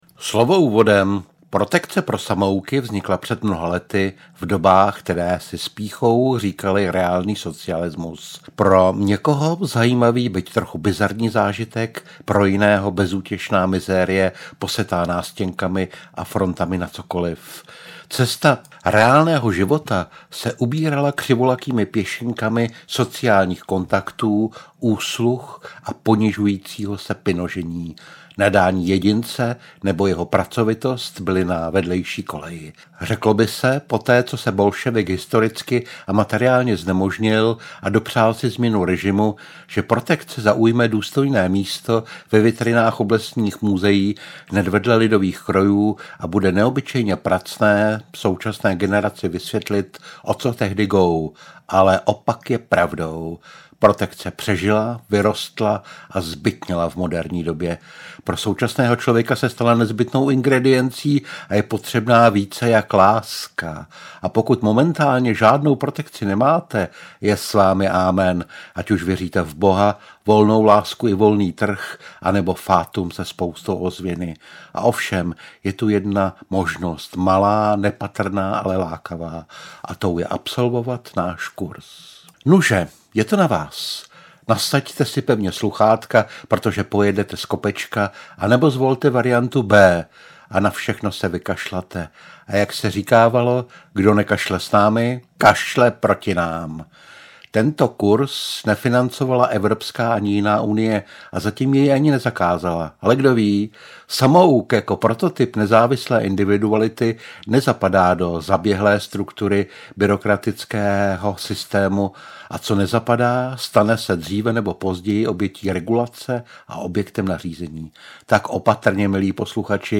Ukázka z knihy
protekce-pro-samouky-audiokniha